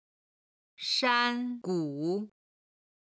香菇(xiāng gū)シイタケ
山谷(shān gǔ)谷間